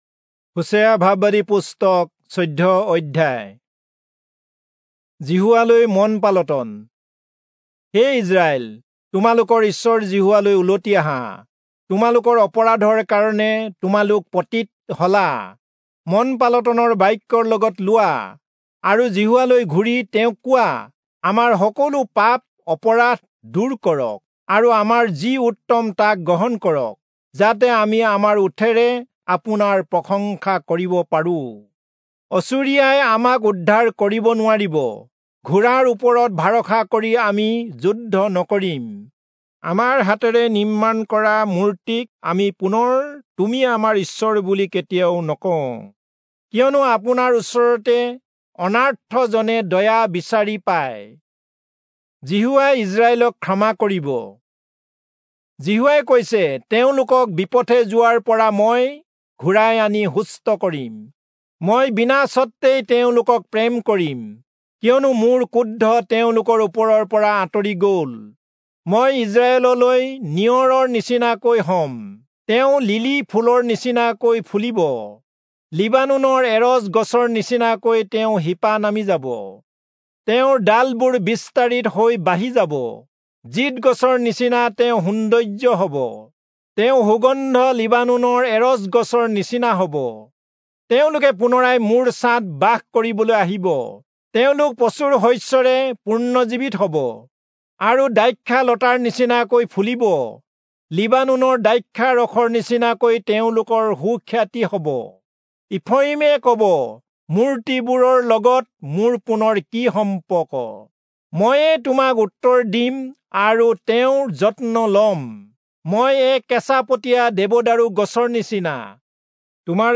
Assamese Audio Bible - Hosea 5 in Irvpa bible version